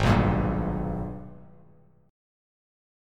G#mM9 chord